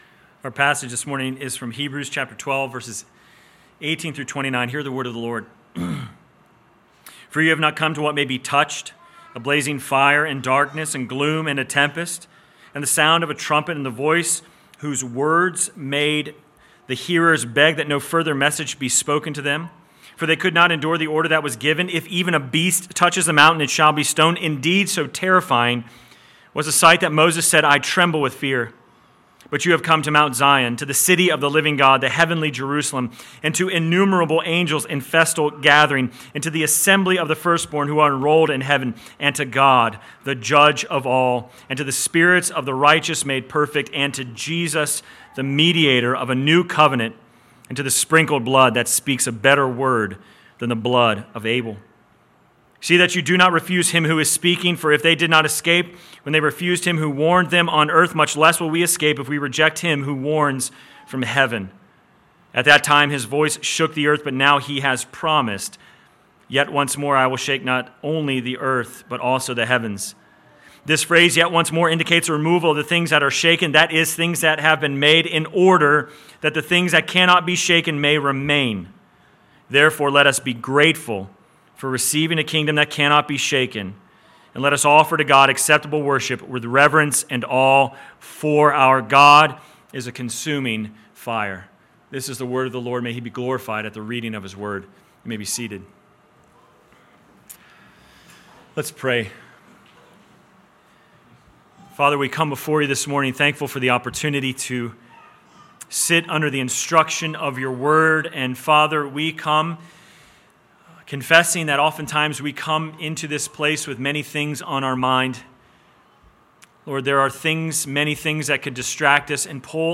Sermon Text: Hebrews 12:18-29 First Reading: Ex. 19:9-20, Deut. 4:23-24 Second Reading: Hebrews 1:1-2:4